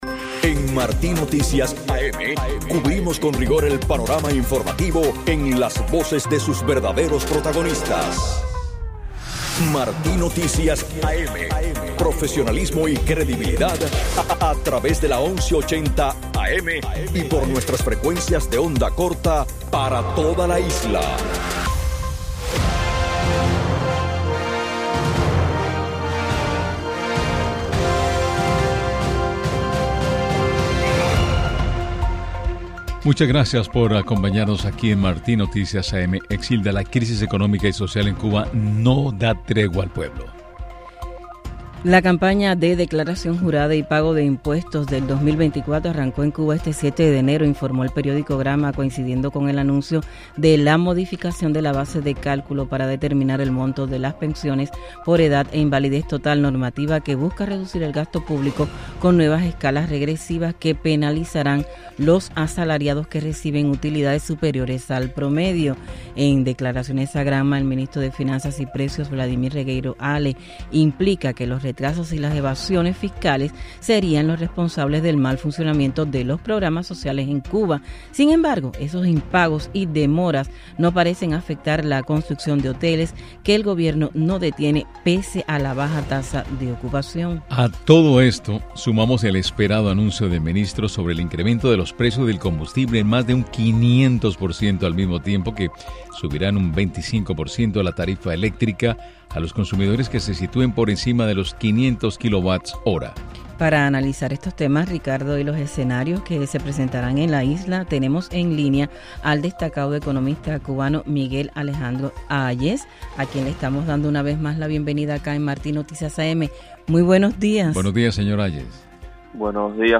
En entrevista con la revista informativa Martí Noticias AM